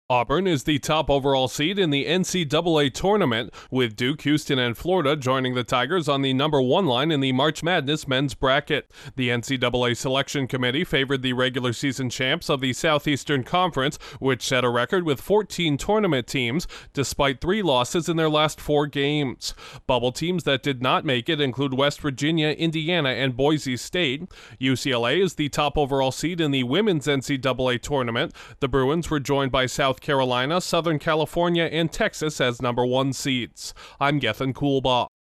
An SEC team claimed the top spot in the men’s March Madness bracket and a Big Ten school sits atop the women’s bracket. Correspondent